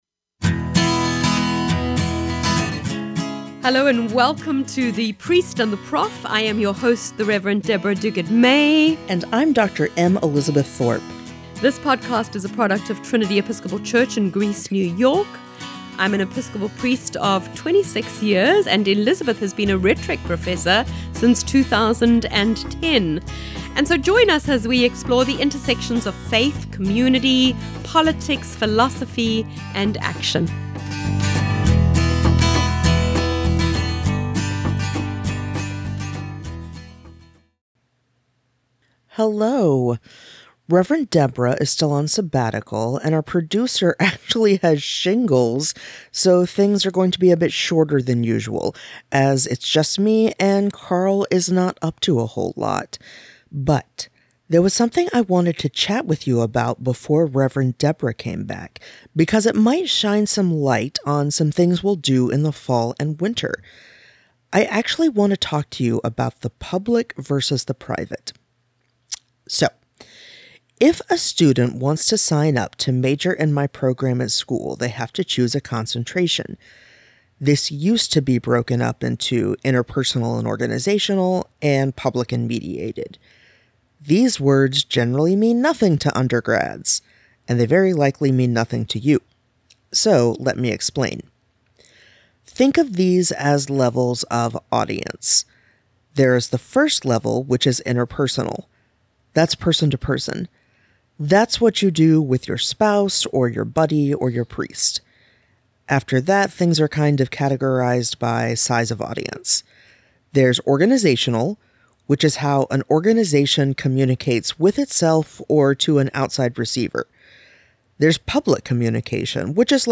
The Priest & The Prof is a conversation about faith, culture, and what it means to be a progressive Christian in the 21st century – without all the fire and brimstone.